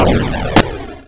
ship_explosion.wav